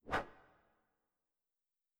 Effects_r1_Move.wav